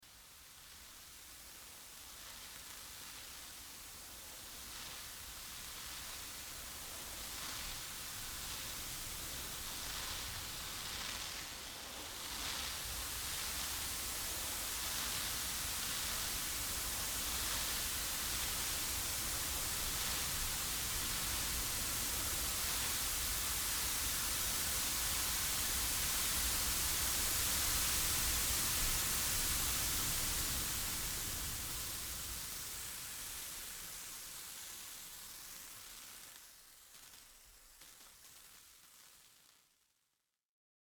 Violin and electronics